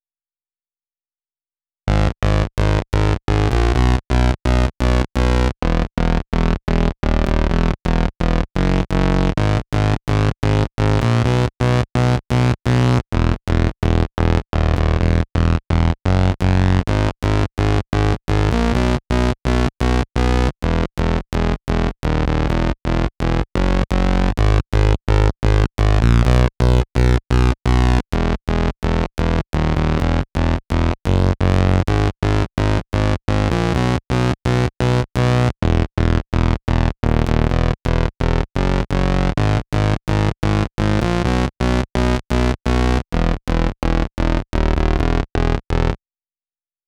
VTDS2 Song Kit 13 Vocoder Mother Earth Low Synth.wav